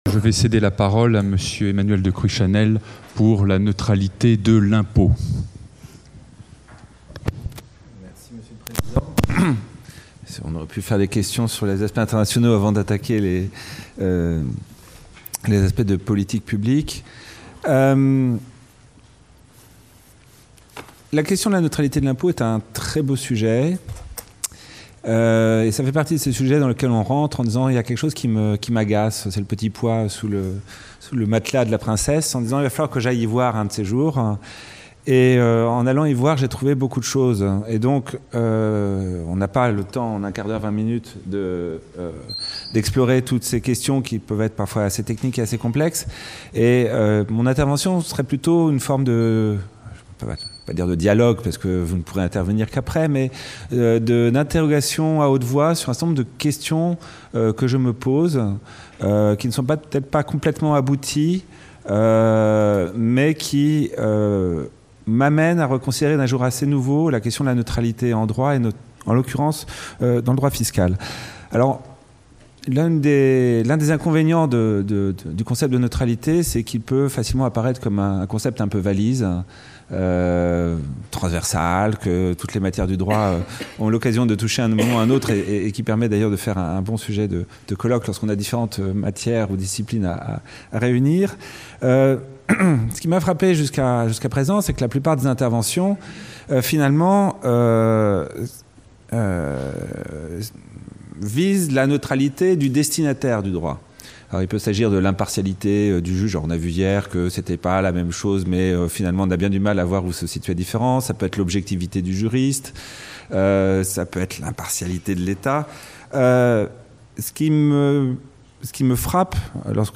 Retour sur le colloque : La neutralité (13 et 14 octobre 2016) Colloque de la promotion 2014 de l'agrégation de droit public Le colloque des 13 et 14 octobre 2016, réunissant les lauréats et membres du jury du concours d’agrégation de droit public 2014, a été l’occasion de mettre en perspective avec succès la neutralité en droit, en interrogeant ses raisons d’être et ses formes, mais en insistant aussi sur ses limites voire ses impasses.